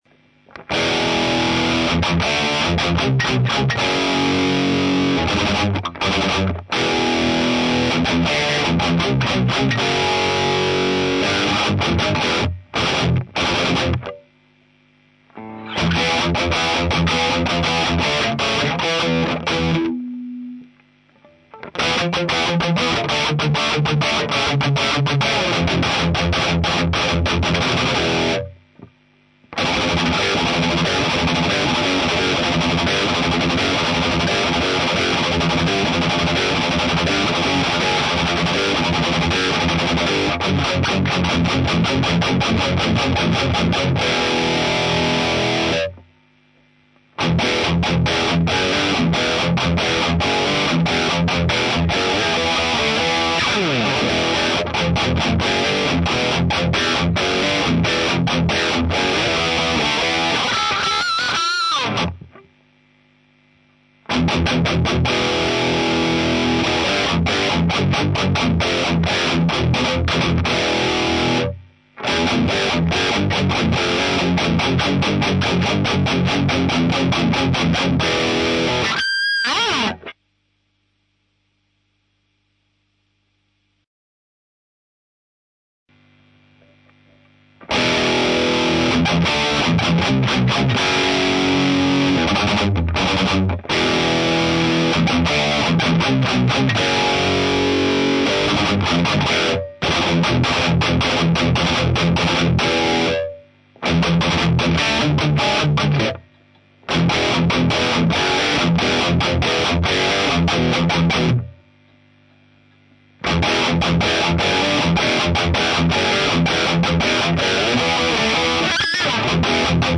Кое что изменил в "голове"... вот еще сравнение 6н2/12ах7...